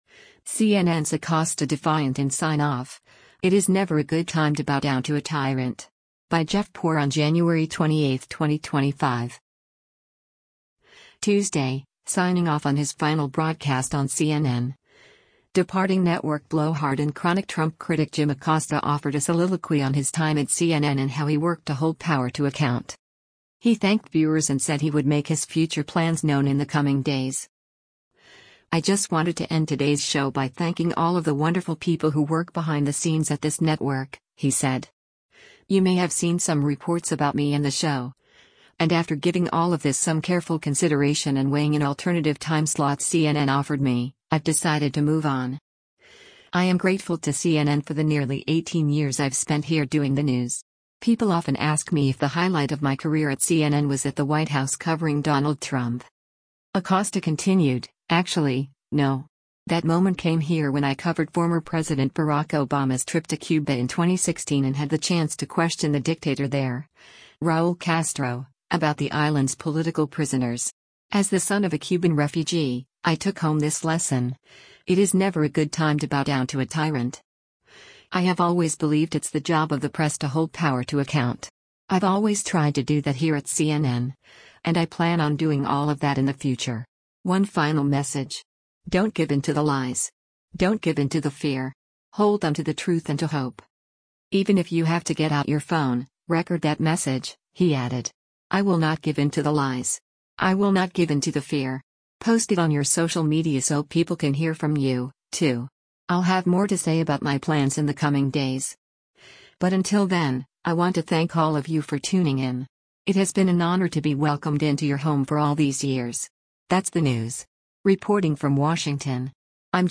Tuesday, signing off on his final broadcast on CNN, departing network blowhard and chronic Trump critic Jim Acosta offered a soliloquy on his time at CNN and how he worked to “hold power to account.”